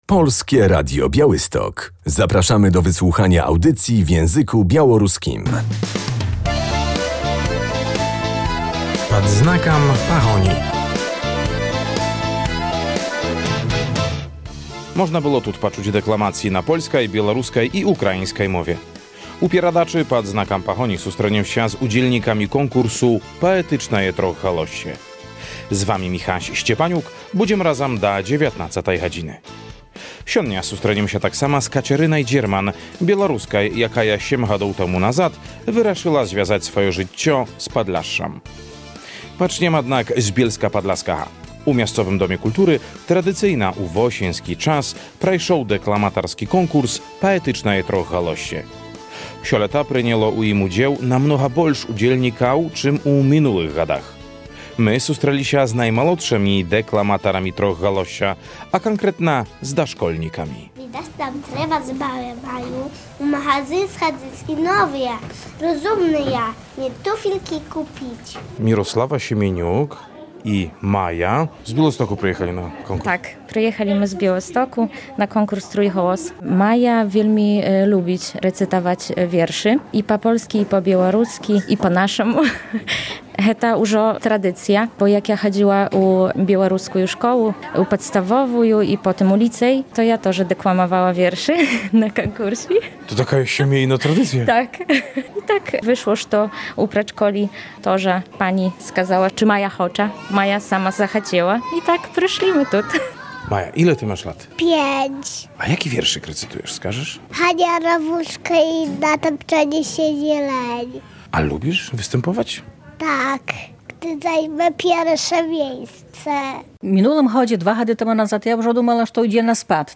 W audycji Pad znakam Pahoni będziemy w Bielsku Podlaskim, gdzie odbył się konkurs Trójgłos Poetycki.